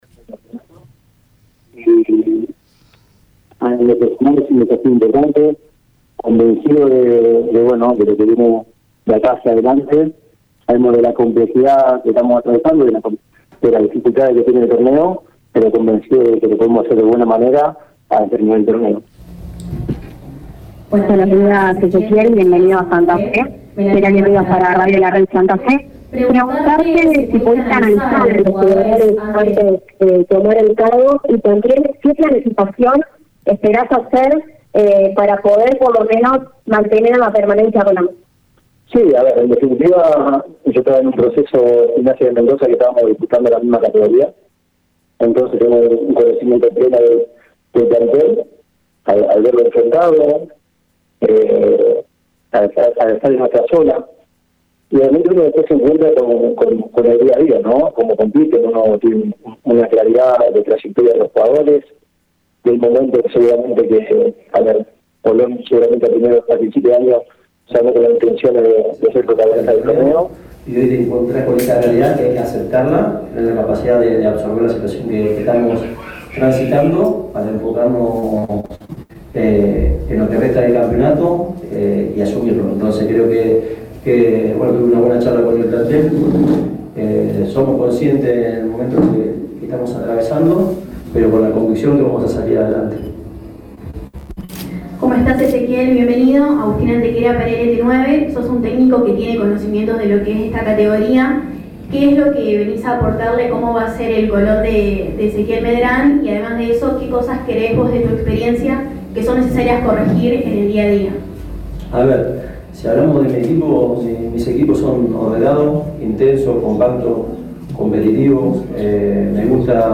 Ezequiel Medrán fue presentado este mediodía en la sala de conferencias en el estadio Brigadier Lòpez.